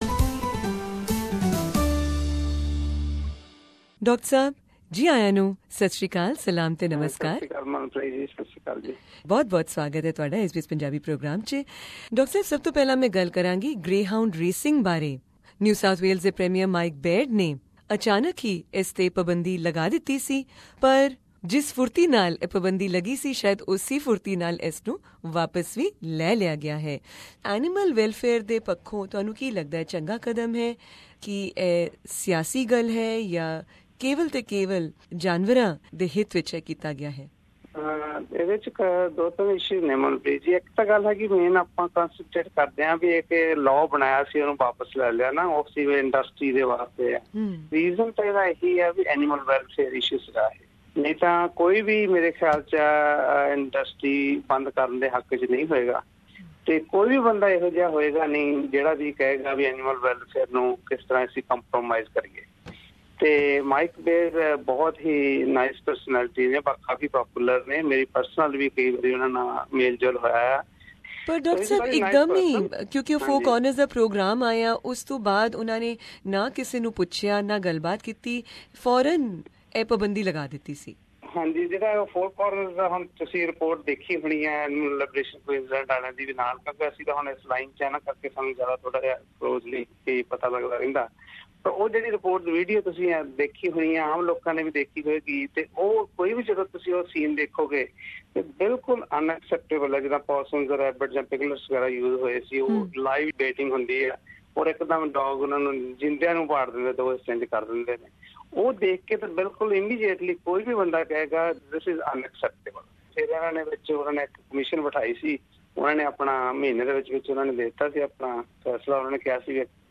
In this interview, we discuss animal welfare issues, not just in greyhound racing, but also in horse, dog, fowl and other forms of animal racing which are popular around the world.